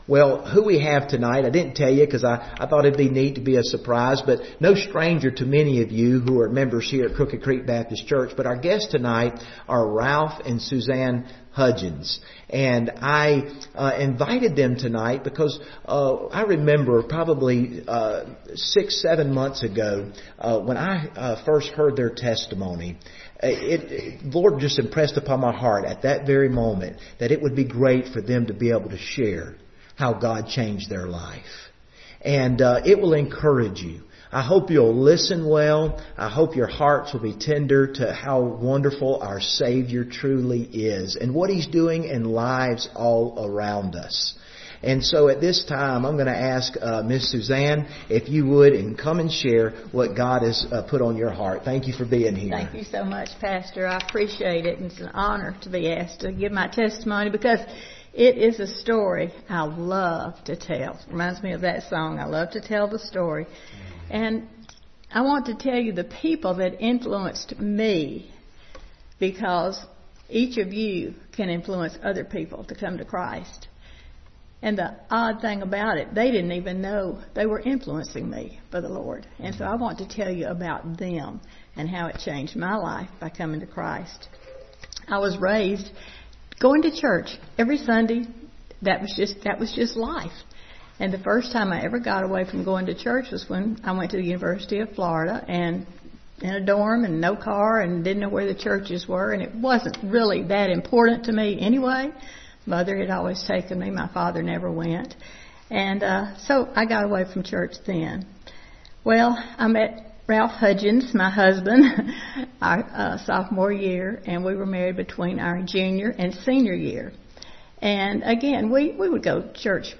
Series: Testimonies
Testimonies Service Type: Wednesday Evening Preacher